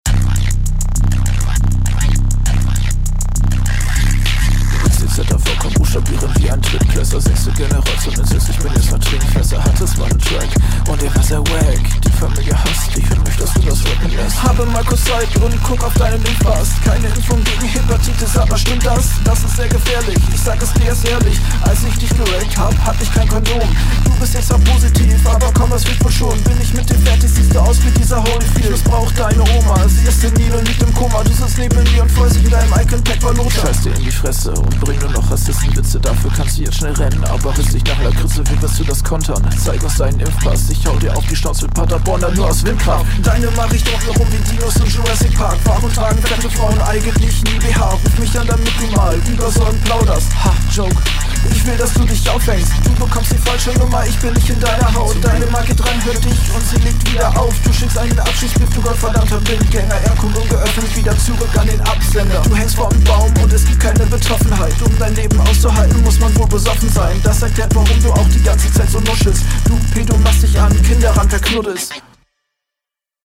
Audio leider an ein paar Stellen schwierig zu verstehen.
Der Beat schadet mehr, als er nützt.